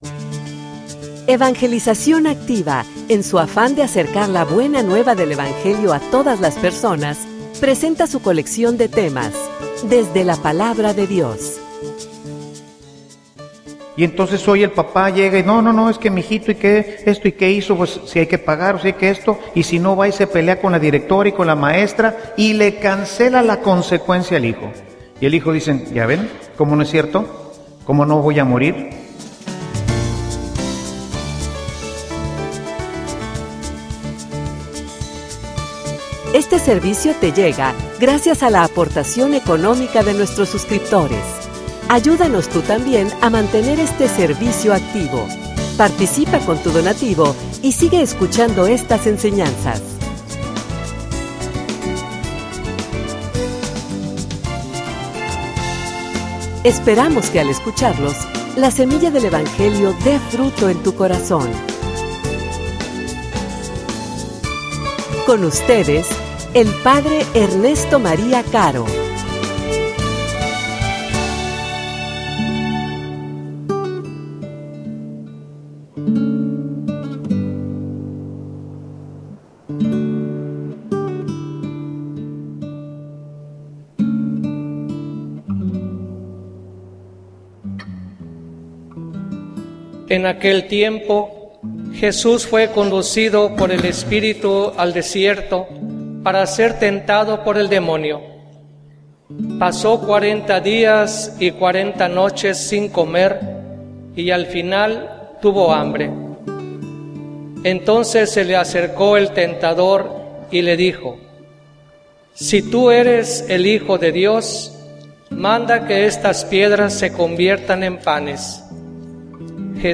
homilia_Ayudalos.mp3